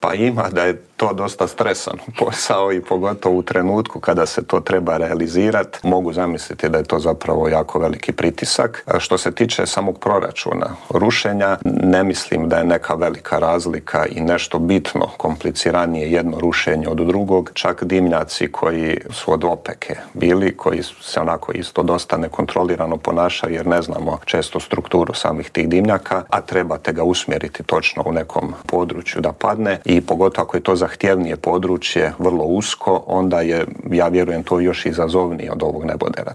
Intervjuu tjedna Media servisa